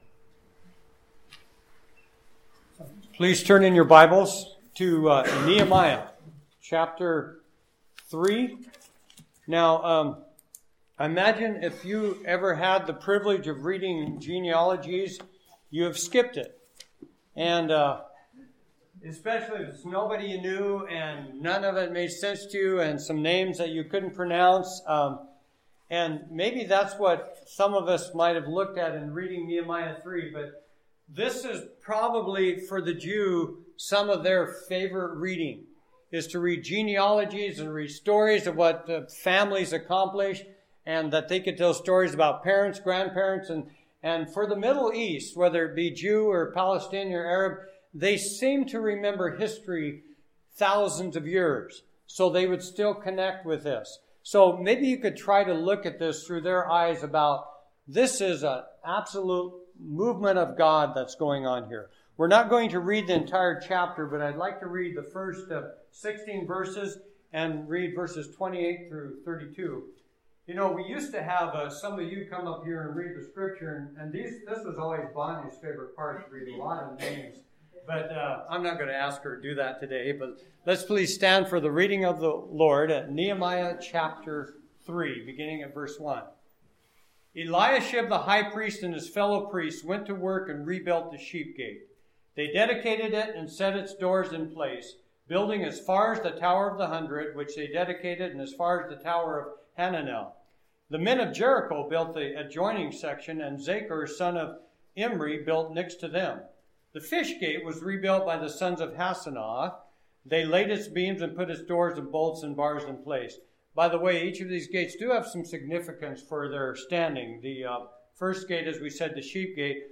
Passage: Nehemiah 3:1-32 Service Type: Sunday Morning Bible Text